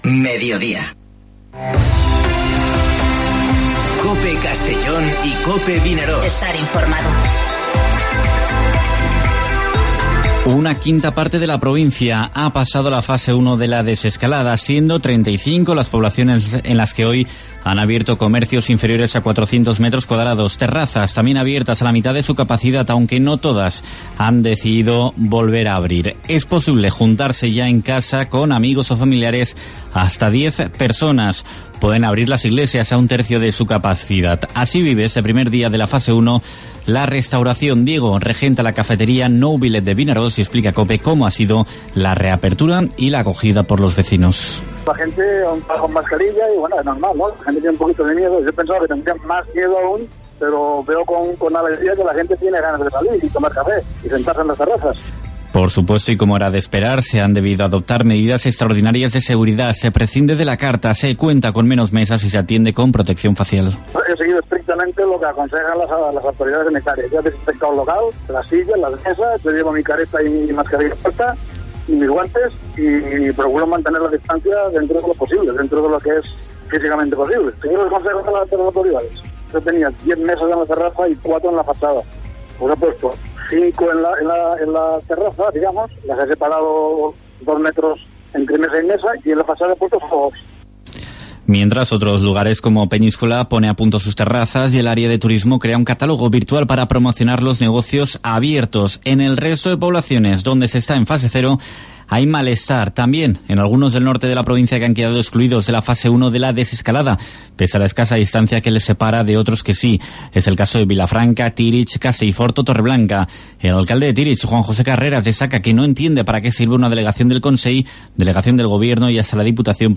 Informativo Mediodía COPE en la provincia de Castellón (11/05/2020)